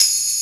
77 TAMB.wav